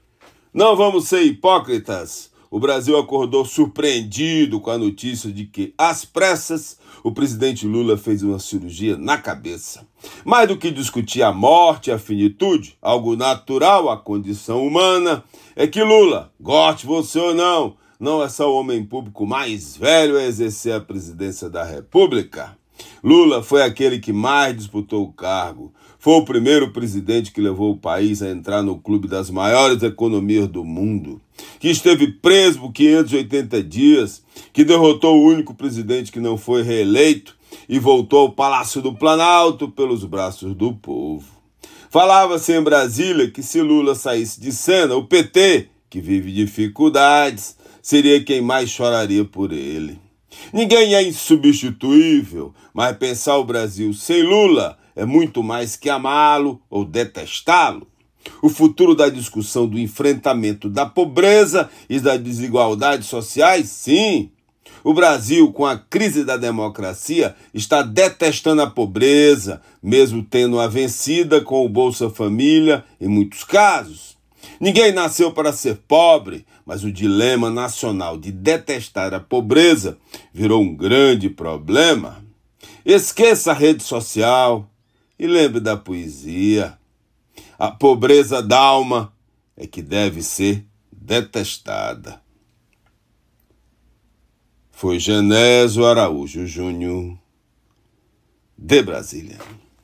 COMENTÁRIO DIRETO DE BRASIL